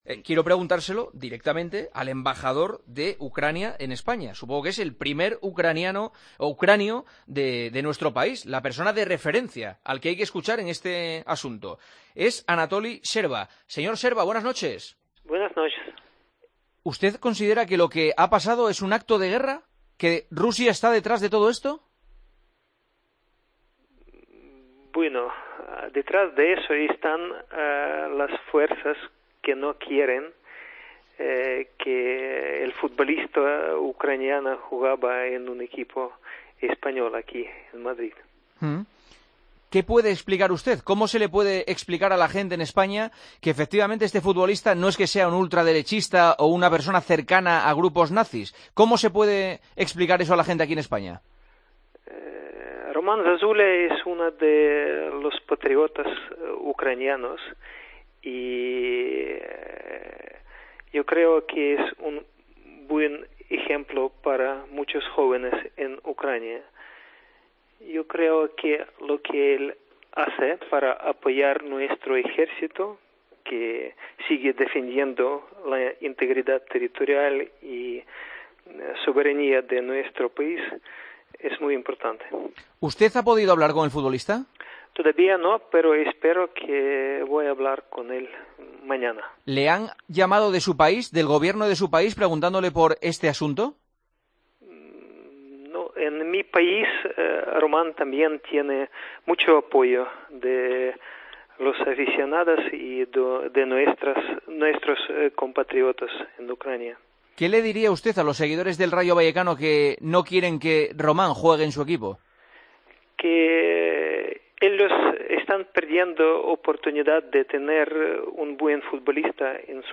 Anatoli Scherva, embajador de Ucrania, en El Partidazo de COPE, sobre el caso Zozulya: "No se puede llevar esa política basada en mentiras"